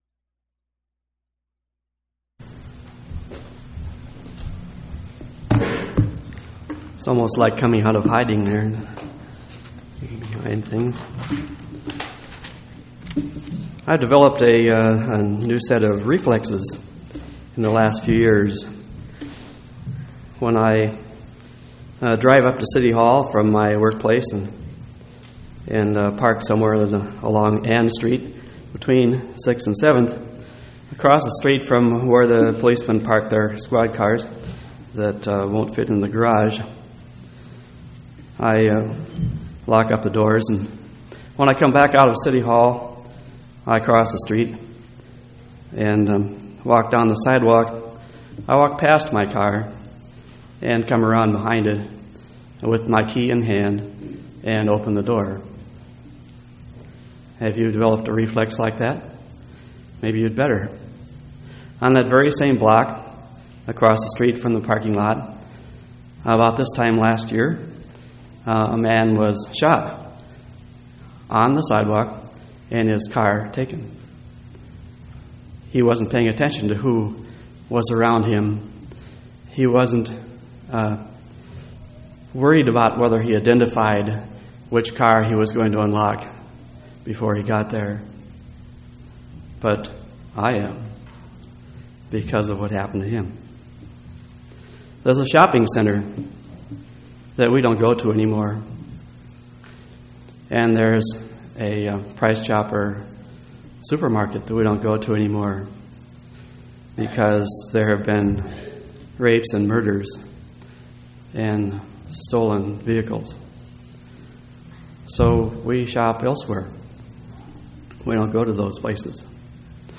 10/24/1993 Location: Temple Lot Local Event